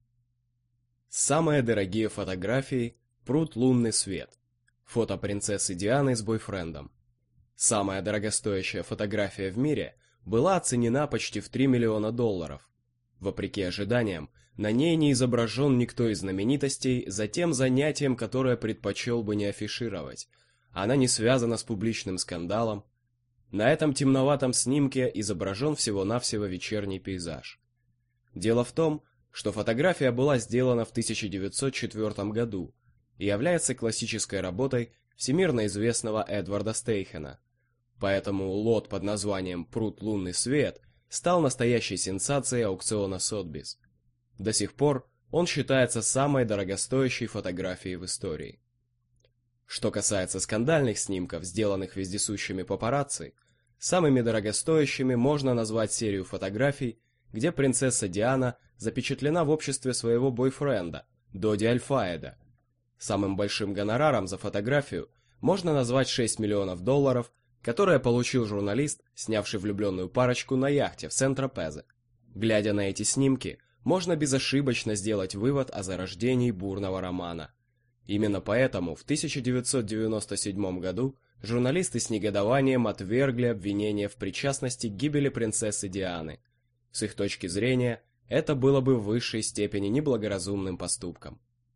Аудиокнига Самые дорогие вещи в мире | Библиотека аудиокниг